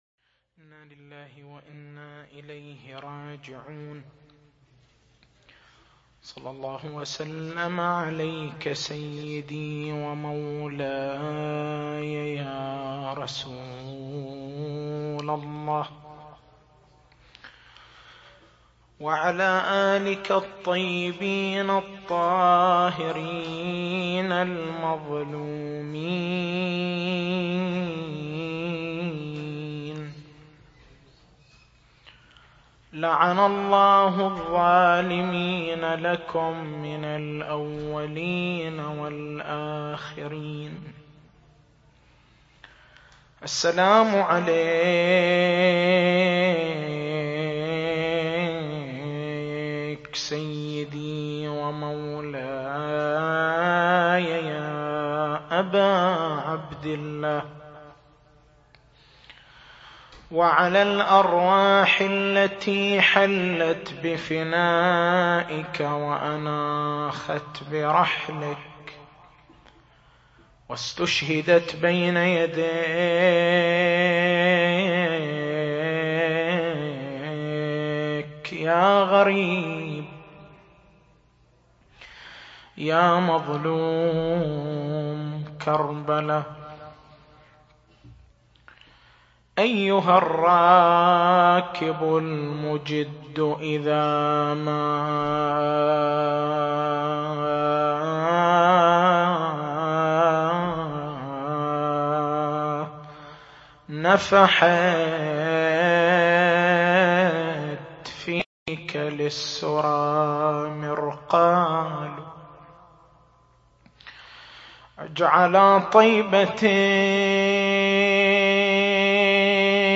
تاريخ المحاضرة: 25/09/1426 نقاط البحث: مفهوم الشهادة بين أربعة معانٍ ثبوت مقام الشهادة للمعصومين (ع)